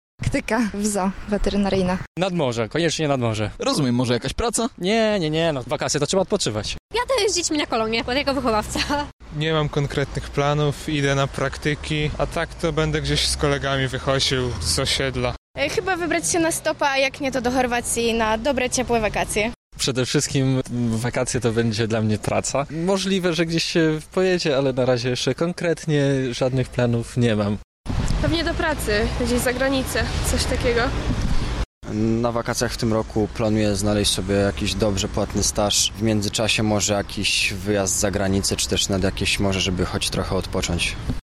Zapytaliśmy lublinian jak to wygląda w ich przypadku. Okazało się że będą oni bardzo zapracowani.